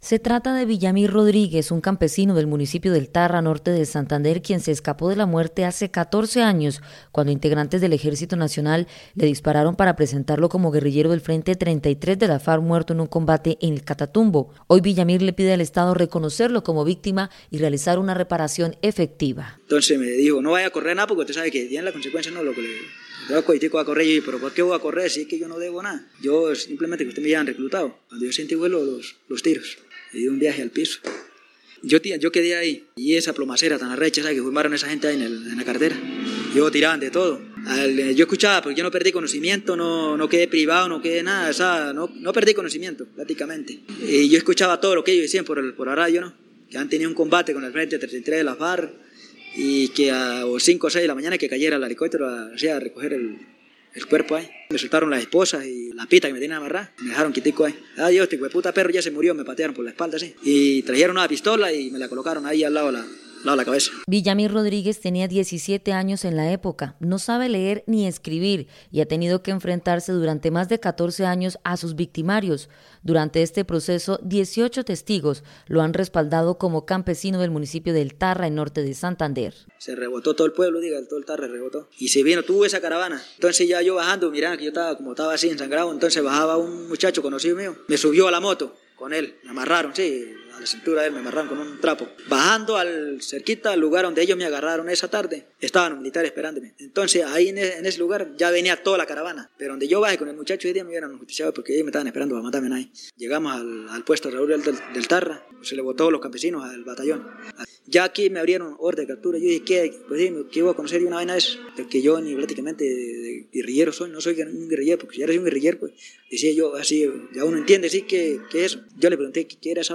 Sobreviviente de falsos positivos en Norte de Santander escuchó a sus victimarios en audiencia pública